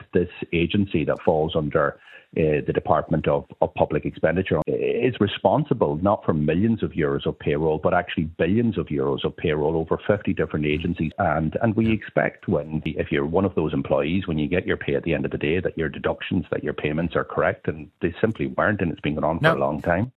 Sinn Fein’s Finance Spokesperson, Donegal Deputy Pearse Doherty, says it’s very concerning: